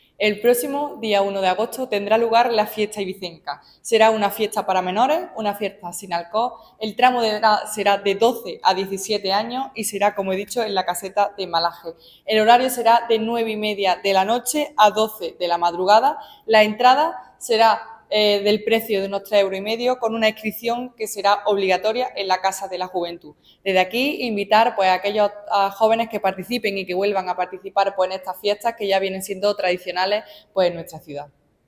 Así lo ha destacado la concejal delegada de Juventud, Marta González, quien ha animado a la participación activa de los jóvenes: “Desde aquí, invitar a aquellos jóvenes que participen y que vuelvan a participar en estas fiestas que ya vienen siendo tradicionales en nuestra ciudad”.
Cortes de voz